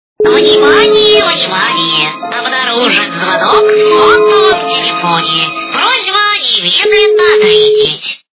Загадочный голос - Внимание, внимание! Обнарежен звонок сотового телефона... Звук Звуки Загадковий голос - Внимание, внимание! Обнарежен звонок сотового телефона...
» Звуки » Смешные » Загадочный голос - Внимание, внимание! Обнарежен звонок сотового телефона...
При прослушивании Загадочный голос - Внимание, внимание! Обнарежен звонок сотового телефона... качество понижено и присутствуют гудки.